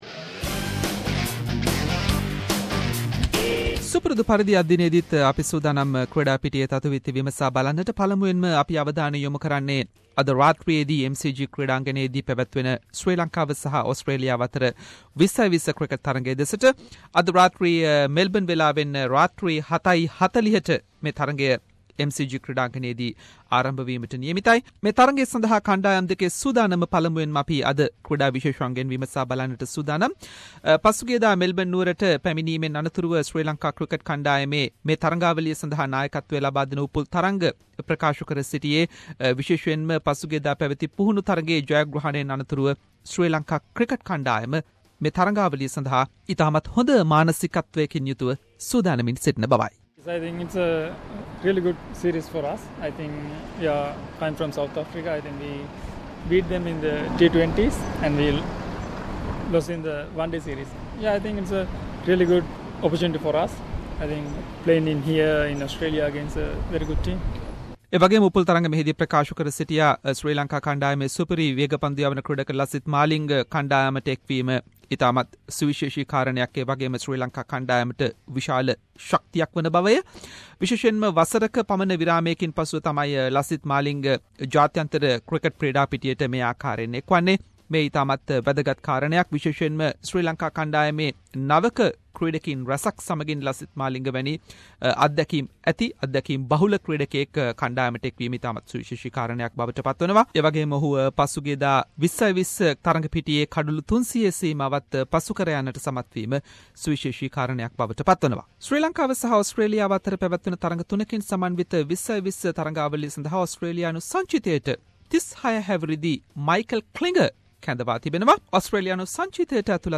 Sports journalist